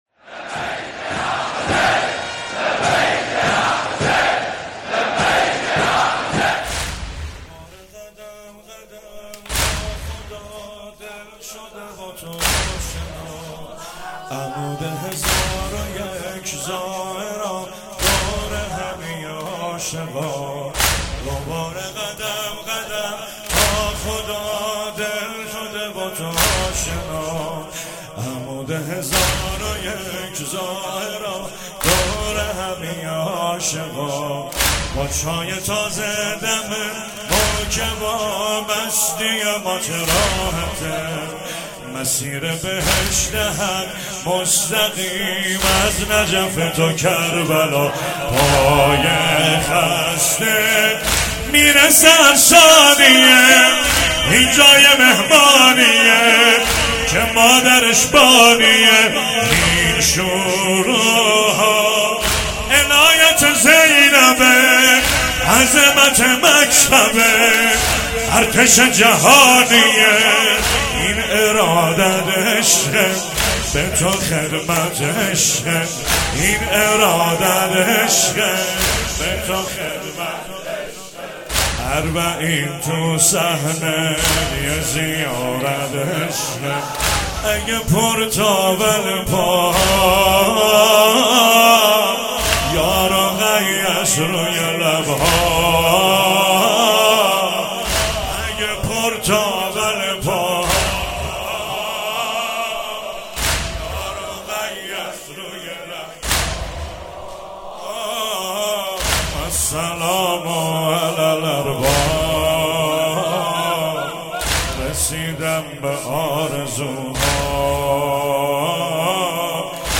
شب اربعین 96 - واحد - اره قدم قدم با خدا دل شده با تو آشنا